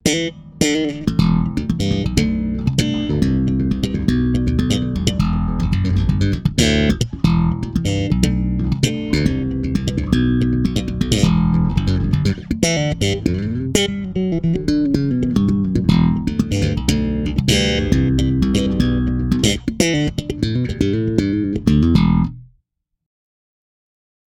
Pretty Slappy | Robbie Reagge | Slap Happy | Bass Swing Guitar Blues | Clean Crunch | Funk 1 | Funk 2 Harp | Jazz 1 | Jazz 2 | Pickin' Rhythm | Rock 1 | Rock 2 |
bassprettyslappy.mp3